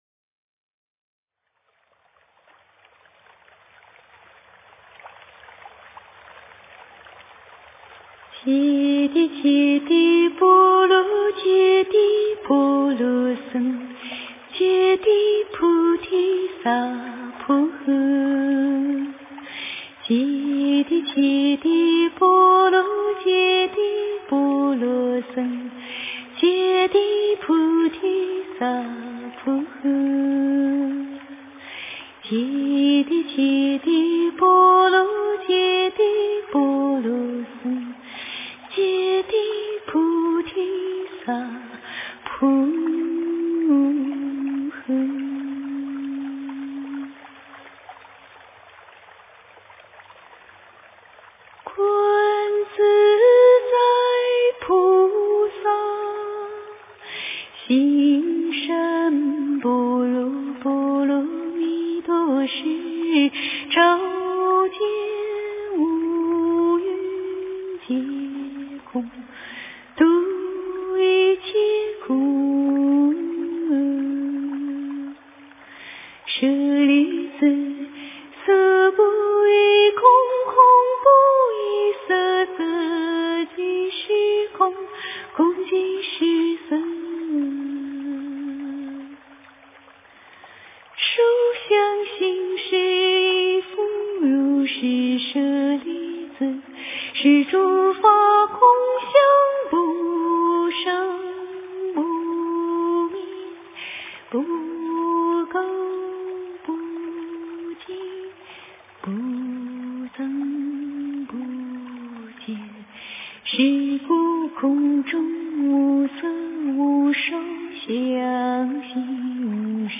心经 诵经 心经--心灵 点我： 标签: 佛音 诵经 佛教音乐 返回列表 上一篇： 大悲咒 下一篇： 大悲咒 相关文章 慈悲的上师--莫尔根 慈悲的上师--莫尔根...